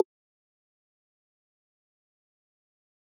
message-sent-instant.ogg